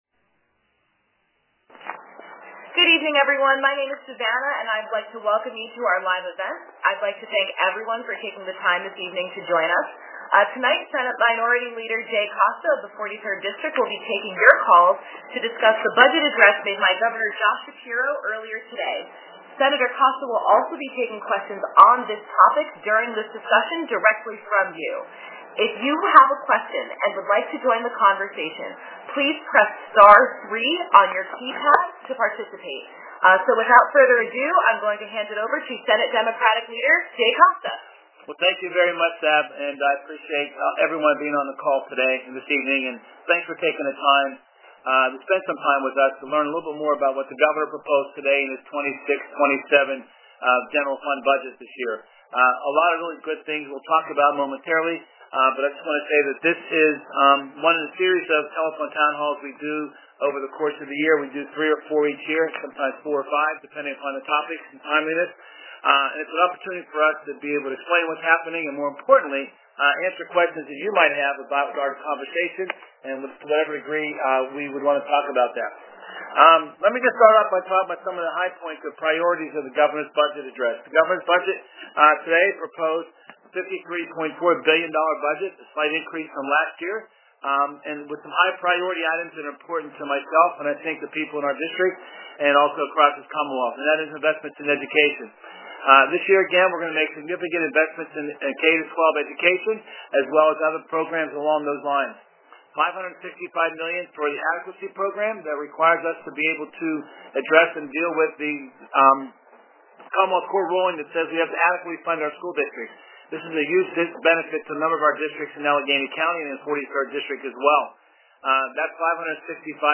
Telephone Town Hall